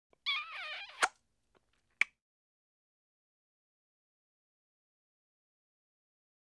Звуки поцелуев
1. Нежный поцелуй в щечку n2.